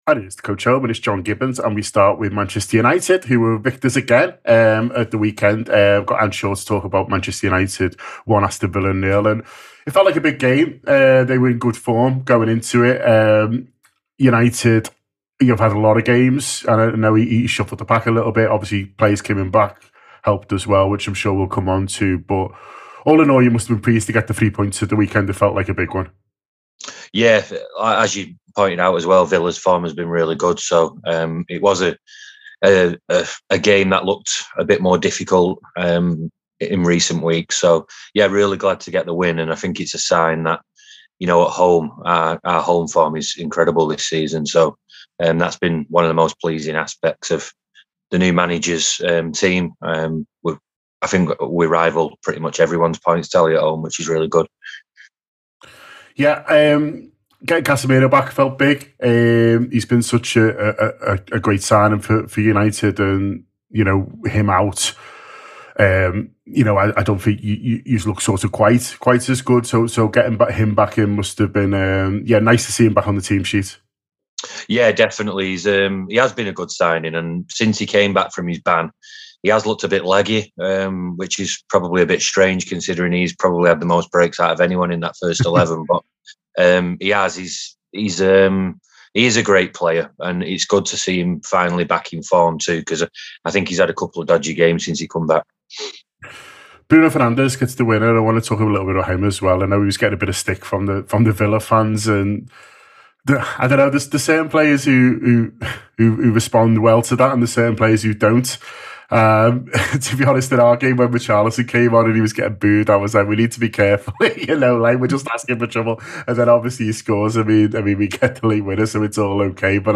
Fan reaction to the weekend’s Premier League games, after Manchester United took a big step towards the top four with a win over Aston Villa.